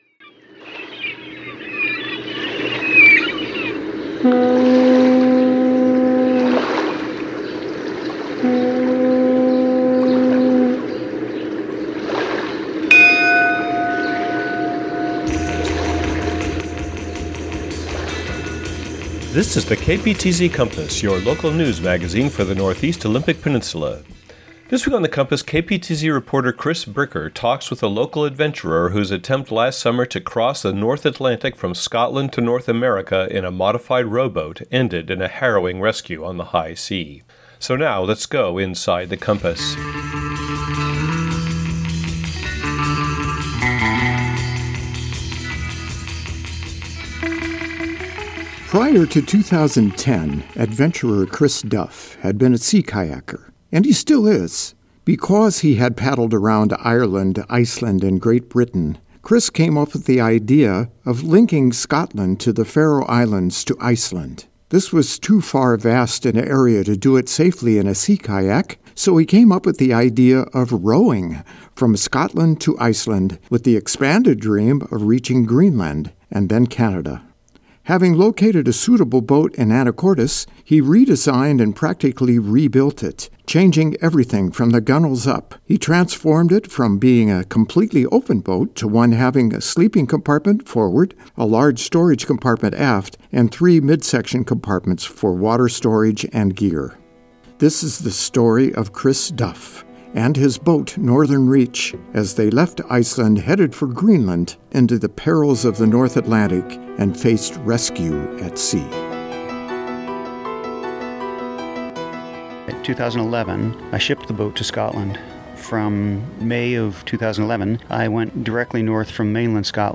Genre: Radio News.